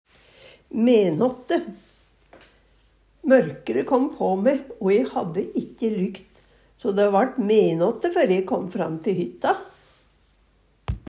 DIALEKTORD PÅ NORMERT NORSK menåtte midnatt, klokka tolv om natta Eksempel på bruk Mørkre kåm på me, o e hadde ikkje lykt, so dæ vart menåtte før e kåm fram te hytta.